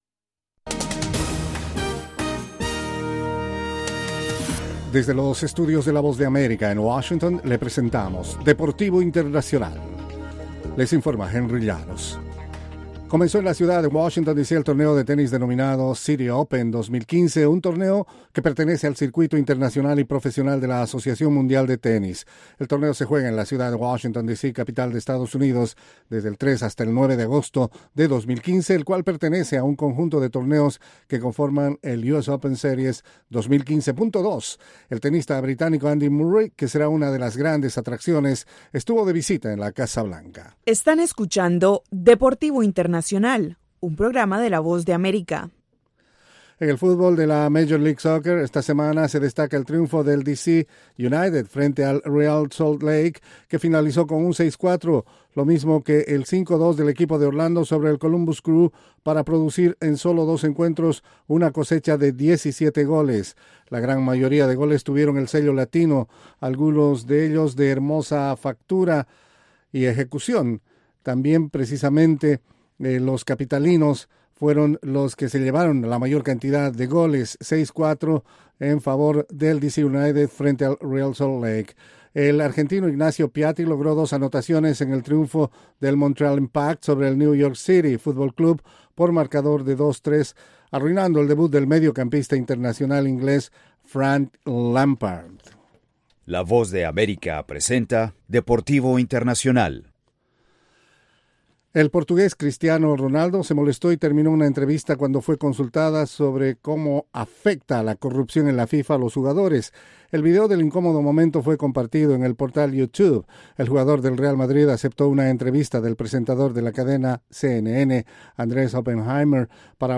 La información deportiva en cinco minutos, desde los estudios de la Voz de América.